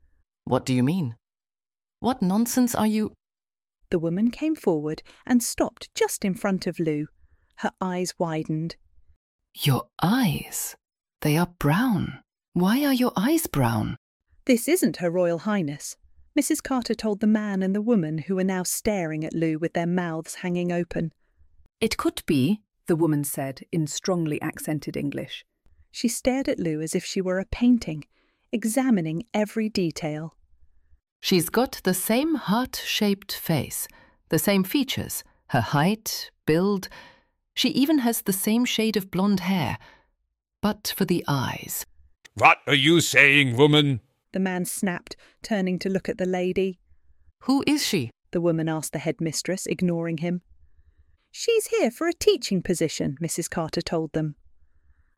This has many voices:
Sample-with-voices.mp3